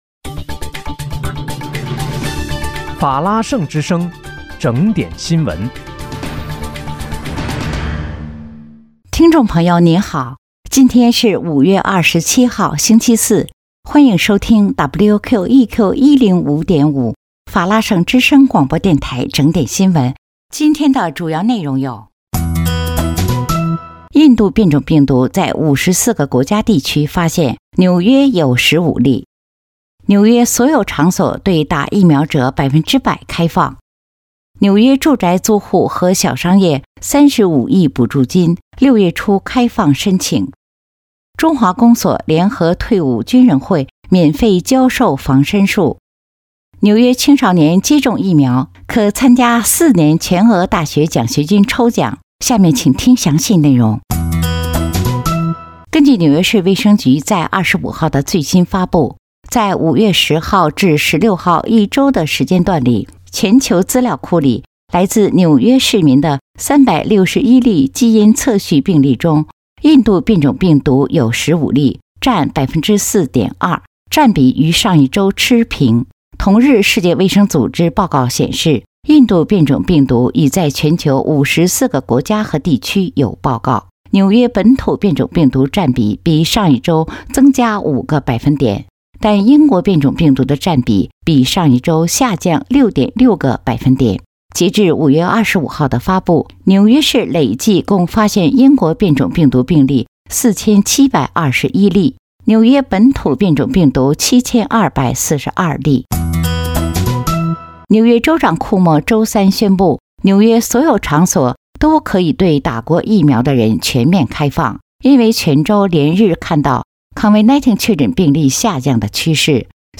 5月27日(星期四）纽约整点新闻